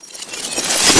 casting_charge_matter_grow_01.wav